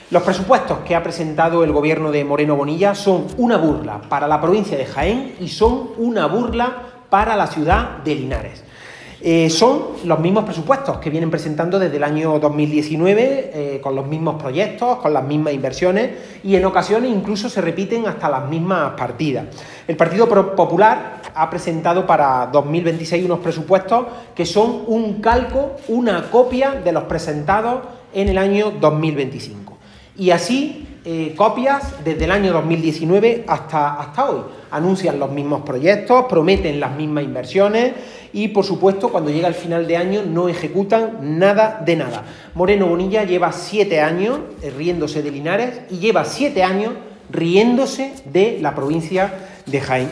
En rueda de prensa en Linares, tras una reunión del Grupo Parlamentario Socialista,
Cortes de sonido